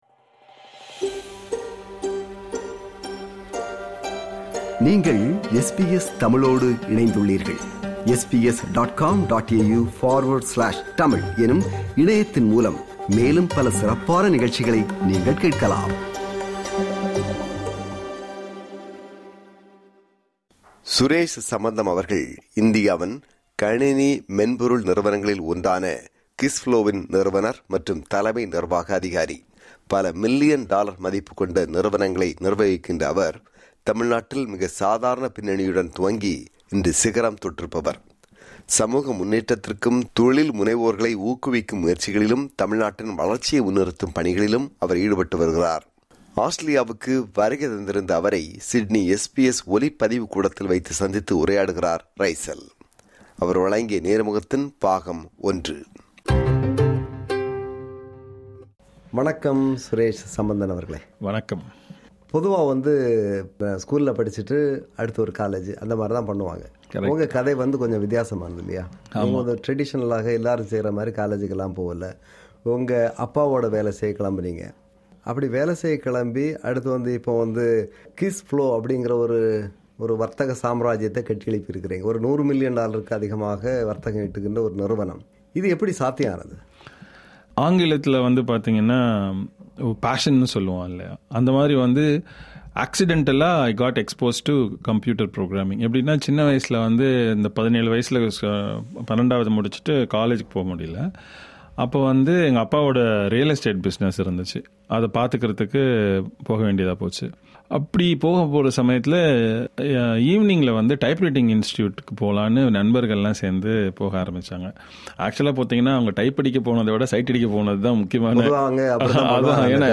சிட்னி SBS ஒலிப்பதிவு கூடத்தில்
நேர்முகம் பாகம் 1